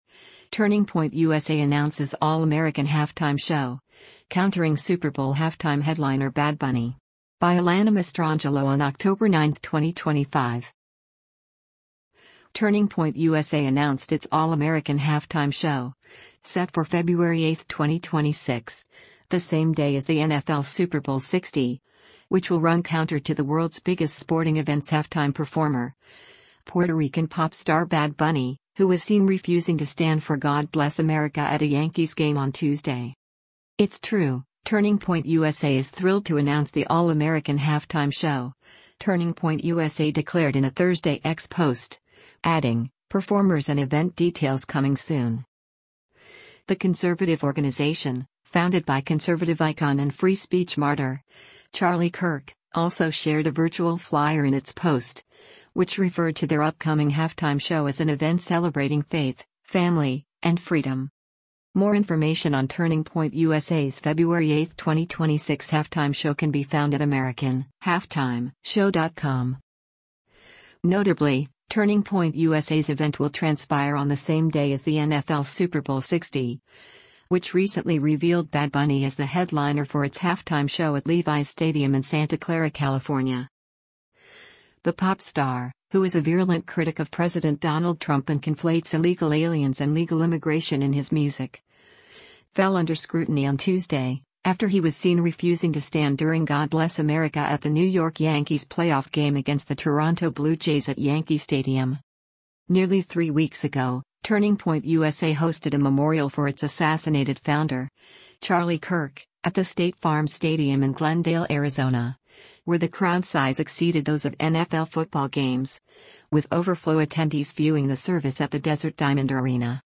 A video clip shows right-wing activist Charlie Kirk at a speaking event in Utah the day he